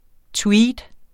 Udtale [ ˈtwiːd ]